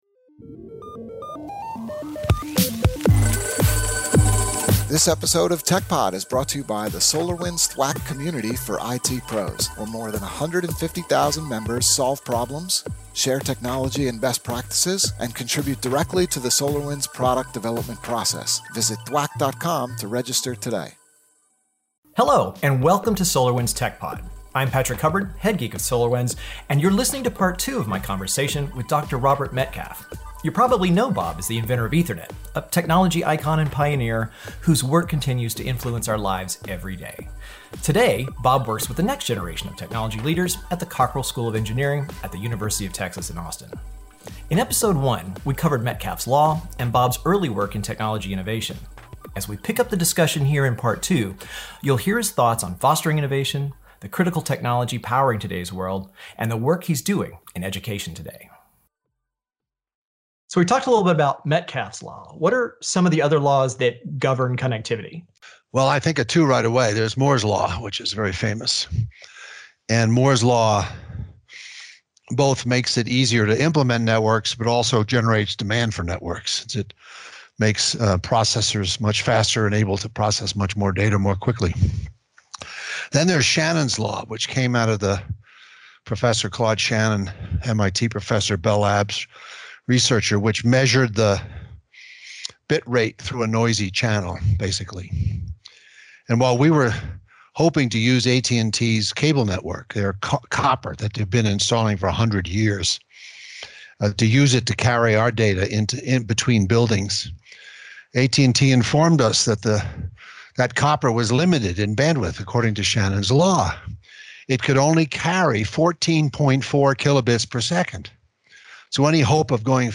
A Conversation with Robert Metcalfe (Part 2)